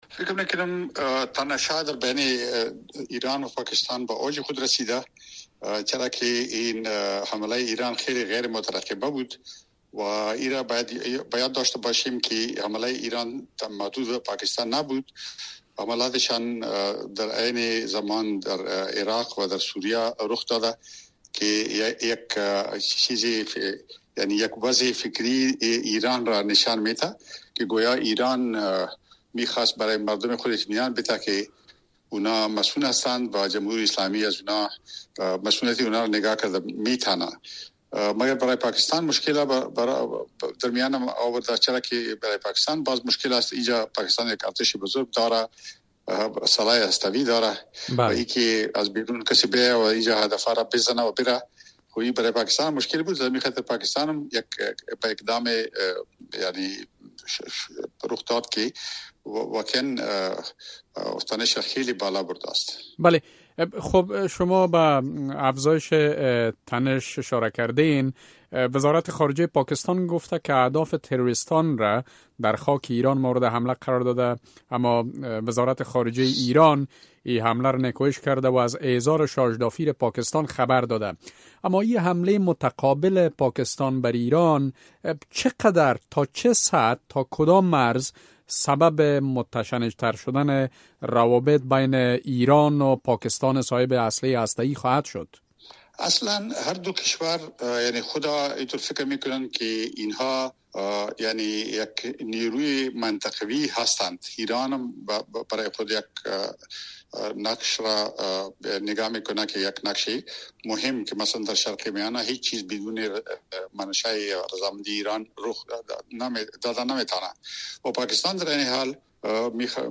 مصاحبه - صدا